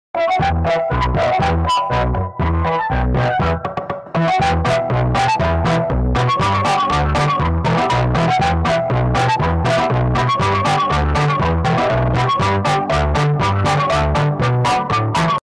Angry Birds Sonido Saturado Sound Effects Free Download